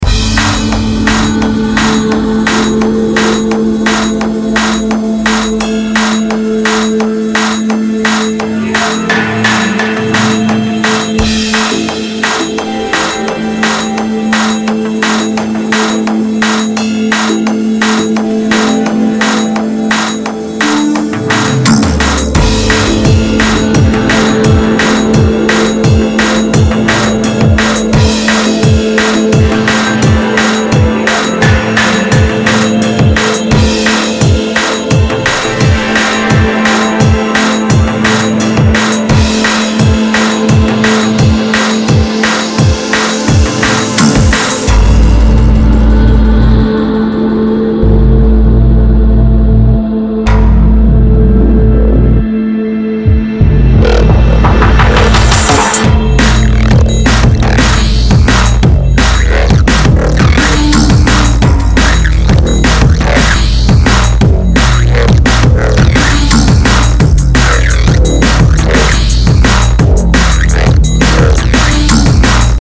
[D*N*B, NeuroFunk]
Всем привет, нашел у себя старую зарисовку и решил набросать с ней трек и вот что получилось. Интро мне нравиться, но вот есть сомнения, что оно не вяжется с басом основной темы, а что вы думаете? Еще я думаю, что сейчас слишком пусто в основной части и может по этому создается такое впечатление?